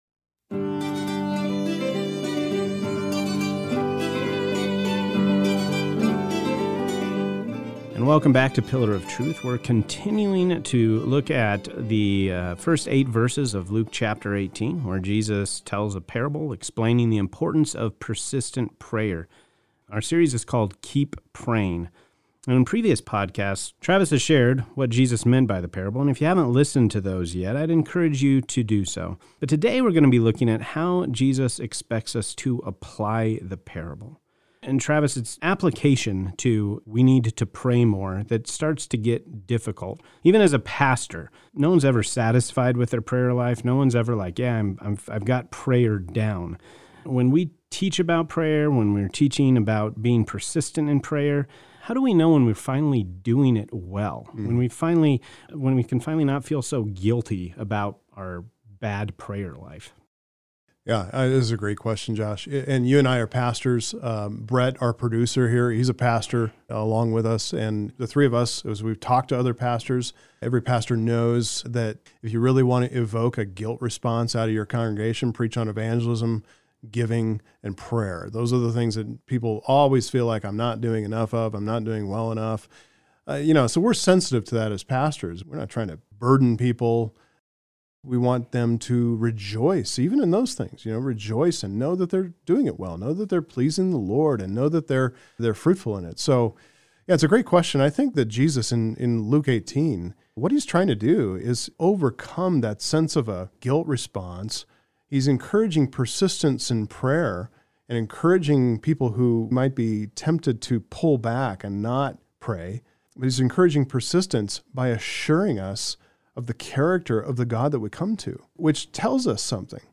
Message Transcript